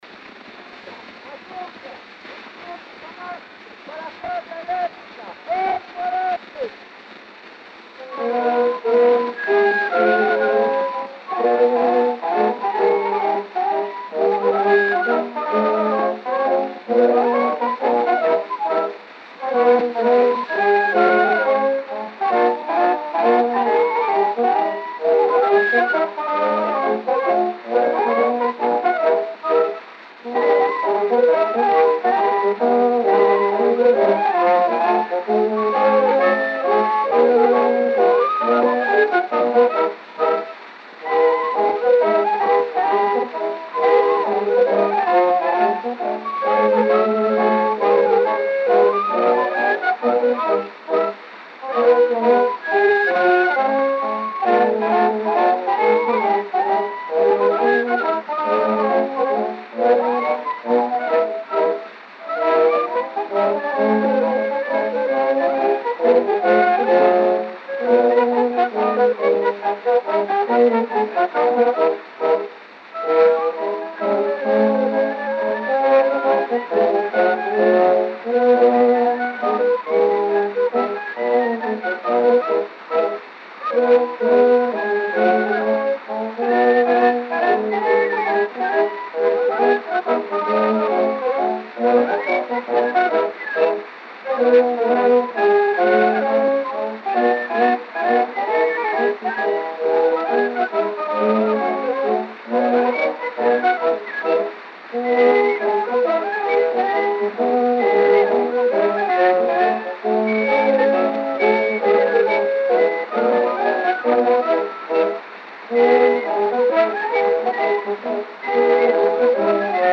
O gênero musical foi descrito como "Mazurka".